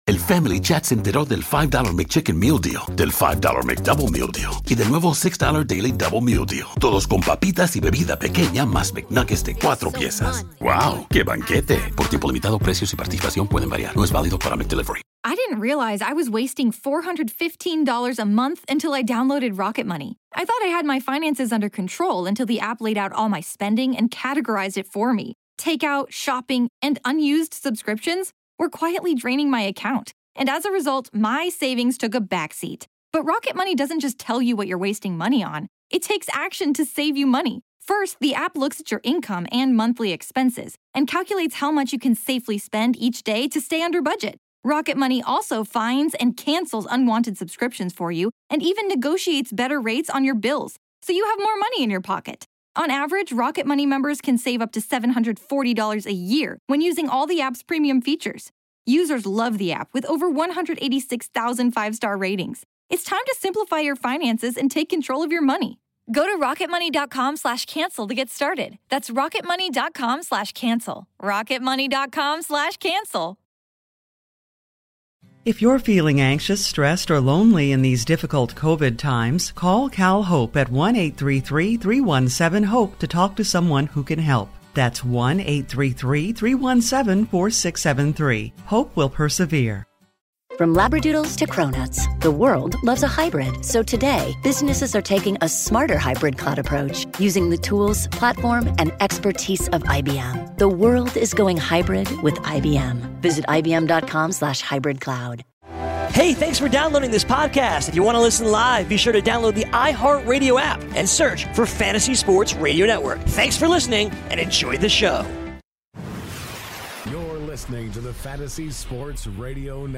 LIVE FROM THE MLB WINTER MEETINGS